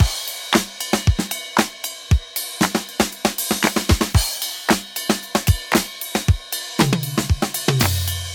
115 Bpm Drum Loop Sample C Key.wav
Free drum beat - kick tuned to the C note. Loudest frequency: 3455Hz
115-bpm-drum-loop-sample-c-key-iiX.ogg